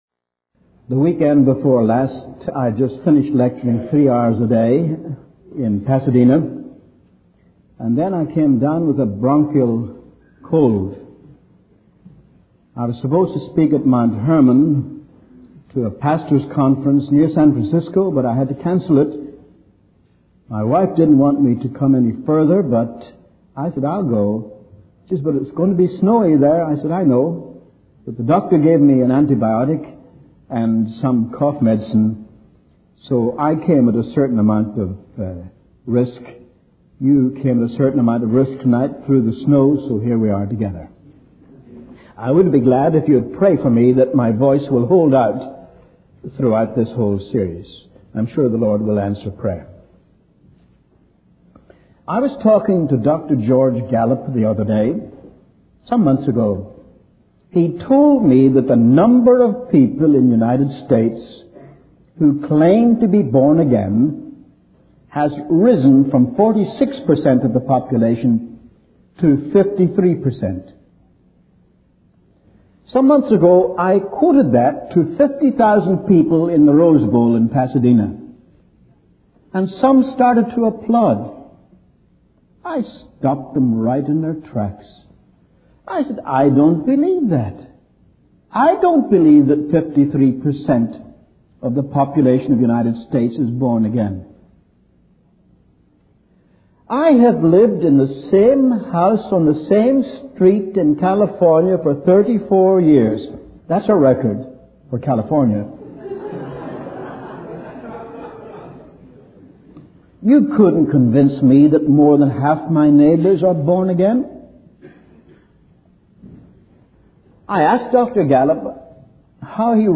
In this sermon, the preacher emphasizes the importance of repentance in the preaching of the gospel.